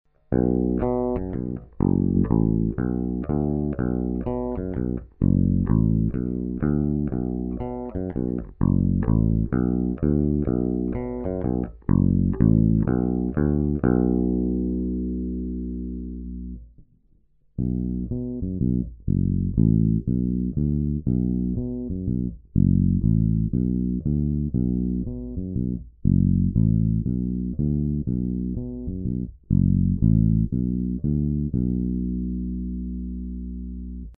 Nahrate piamo do zvukovky a bez uprav smile
2.) Krkovy snimac
I. clona naplno
II: clona stiahnuta